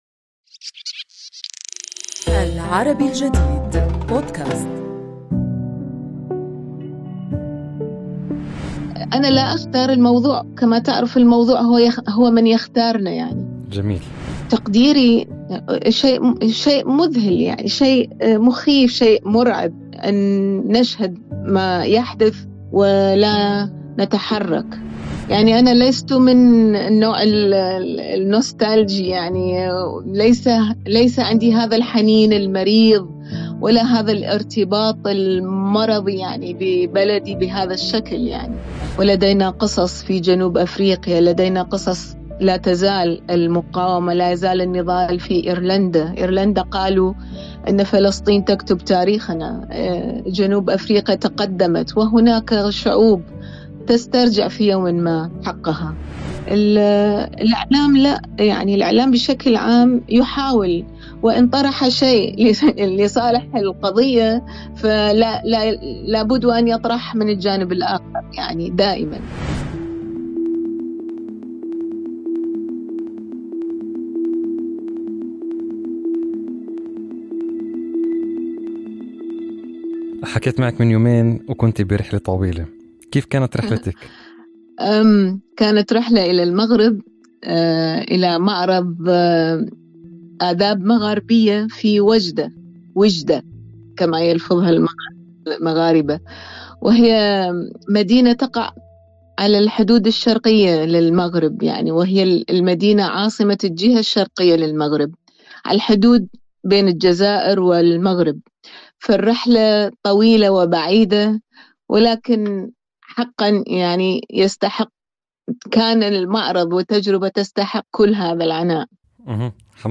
تقرأها لنا بصوتها